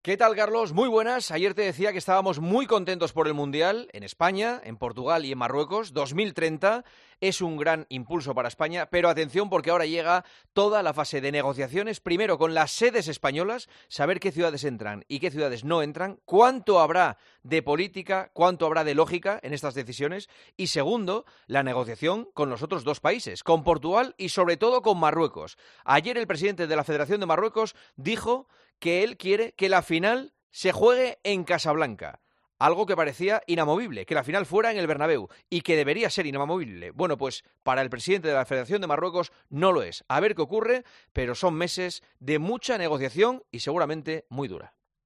El presentador de 'El Partidazo de COPE' analiza la actualidad deportiva en 'Herrera en COPE'